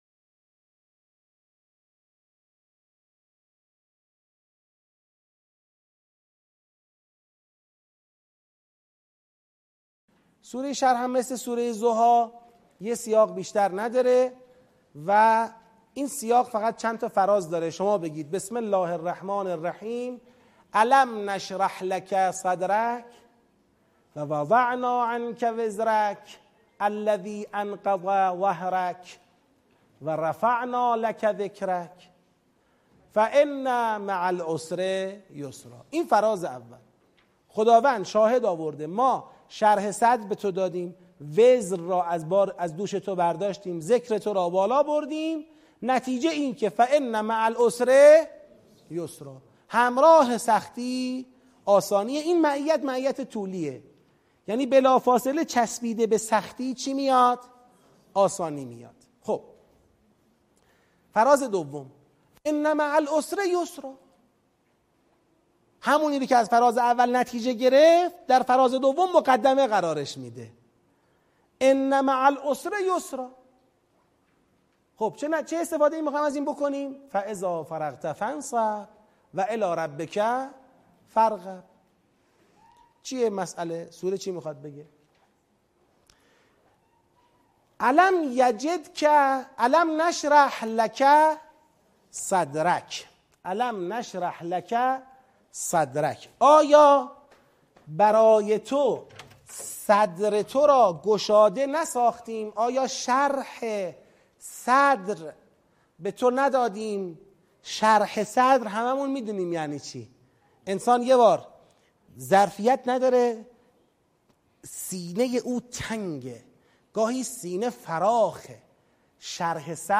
آموزش تدبر در سوره شرح - بخش اول